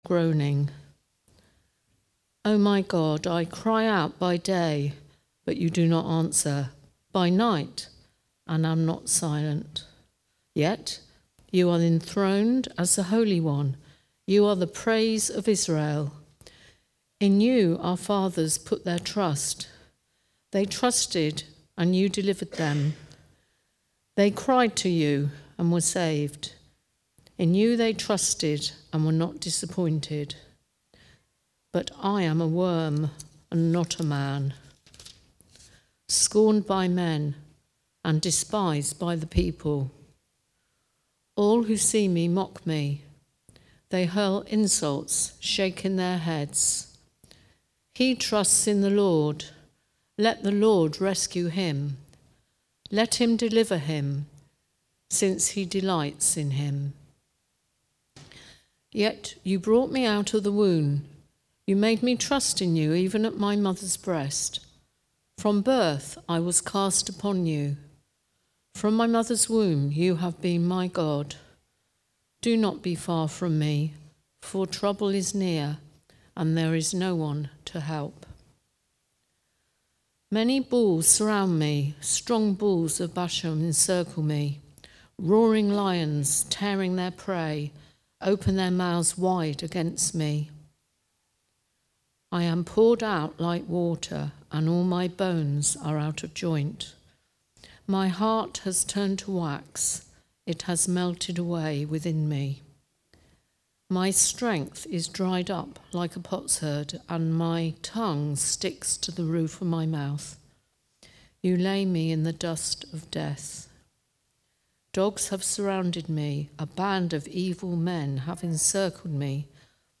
Preaching
Recorded at Woodstock Road Baptist Church on 08 December 2024.